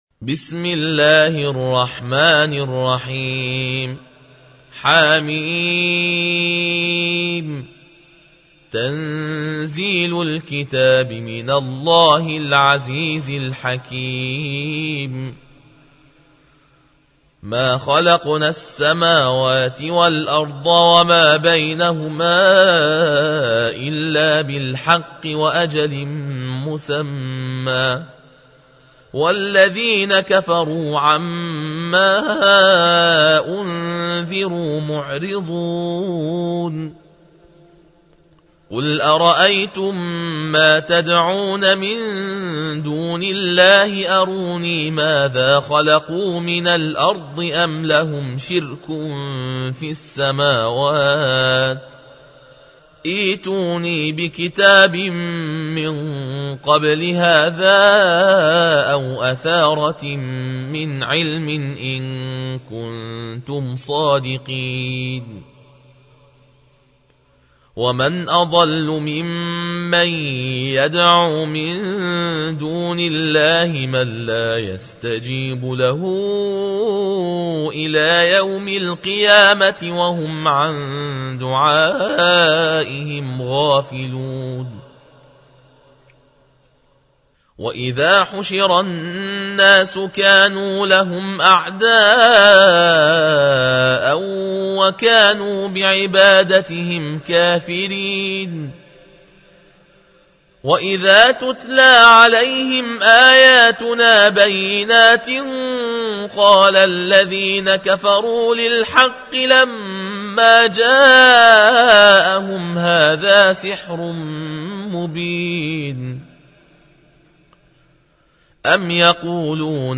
الجزء السادس والعشرون / القارئ